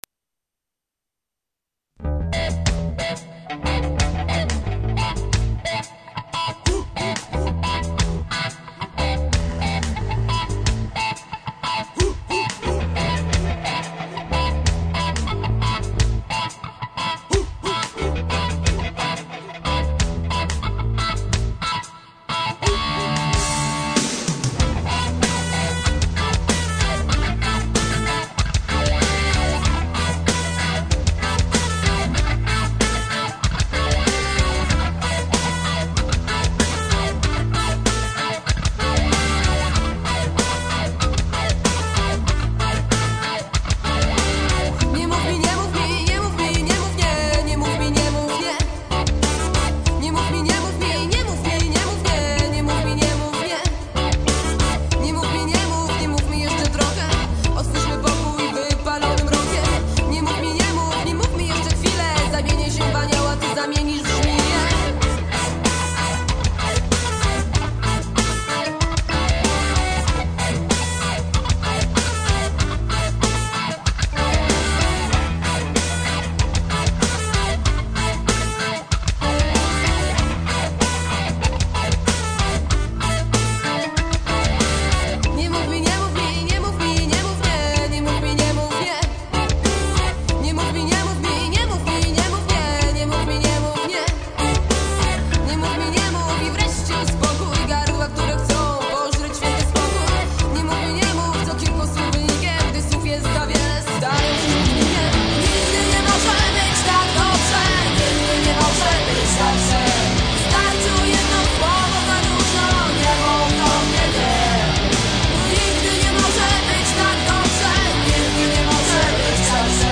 Ce groupe rock polonais, ce n'est pas que de la déconne.